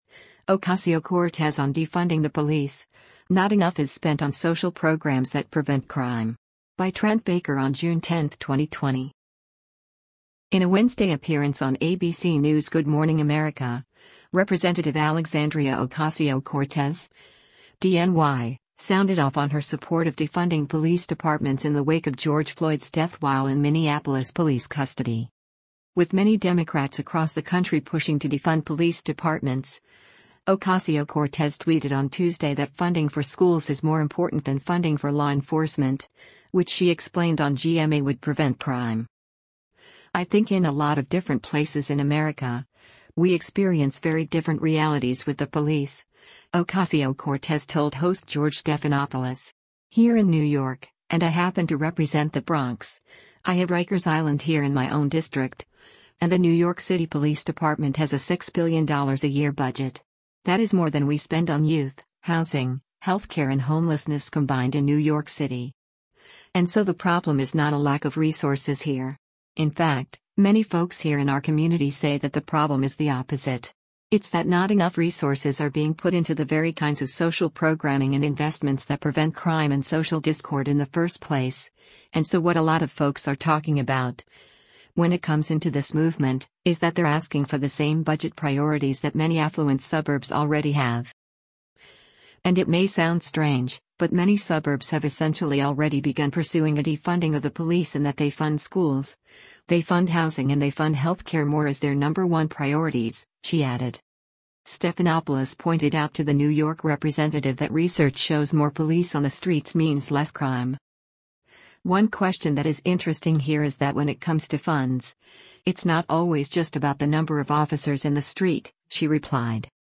In a Wednesday appearance on ABC News’ “Good Morning America,” Rep. Alexandria Ocasio-Cortez (D-NY) sounded off on her support of defunding police departments in the wake of George Floyd’s death while in Minneapolis police custody.
Stephanopoulos pointed out to the New York representative that research shows more police on the streets means less crime.